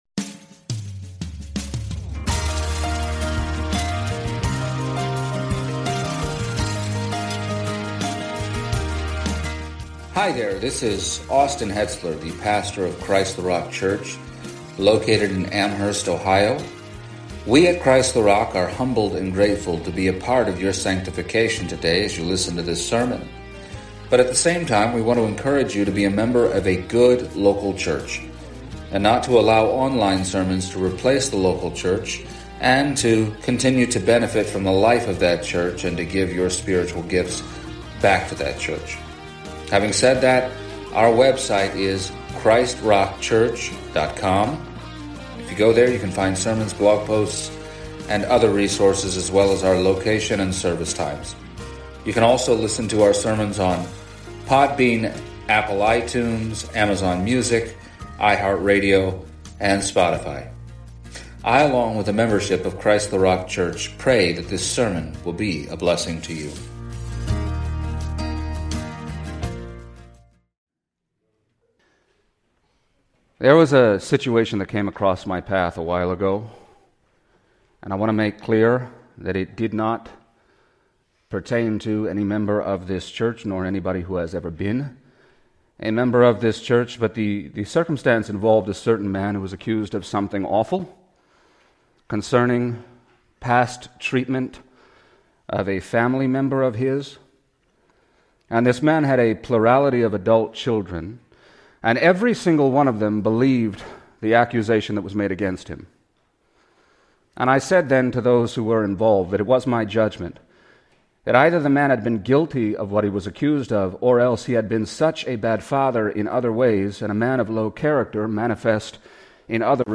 Passage: Acts 20:32-38 Service Type: Sunday Morning